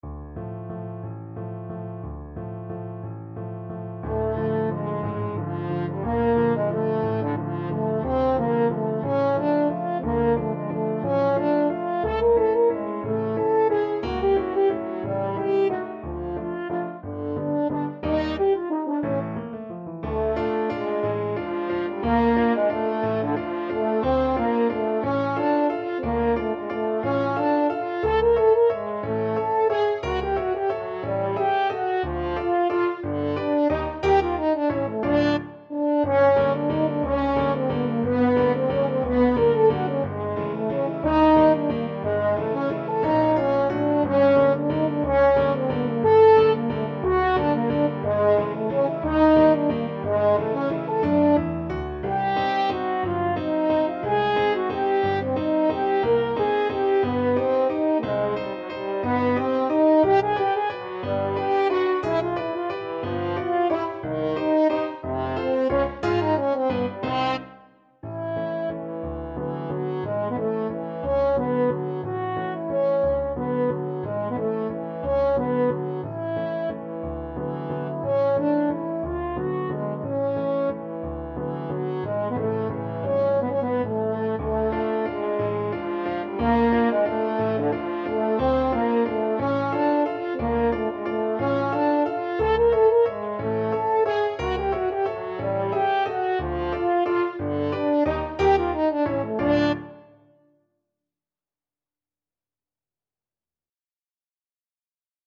3/4 (View more 3/4 Music)
Allegro espressivo .=60 (View more music marked Allegro)
Classical (View more Classical French Horn Music)